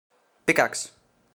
Play, download and share pickaxe original sound button!!!!
pickaxe.mp3